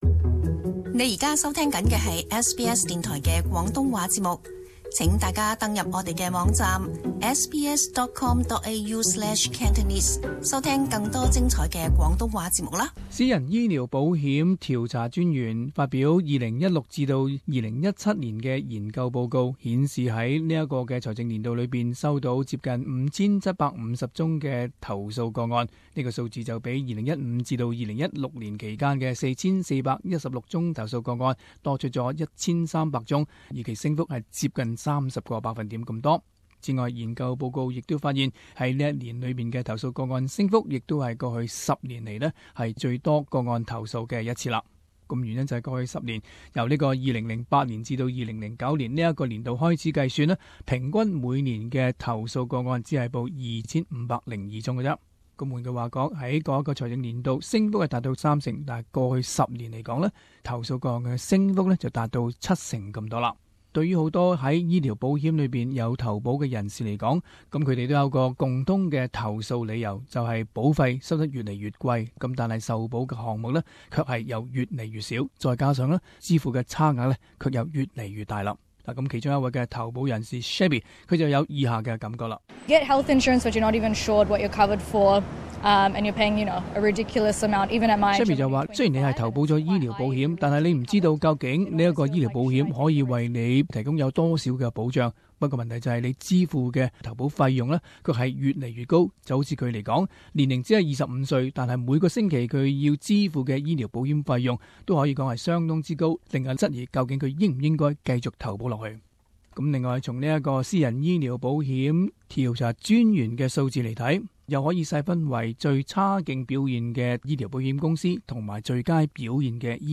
【時事報導】私人醫療保險頭數個案近年劇增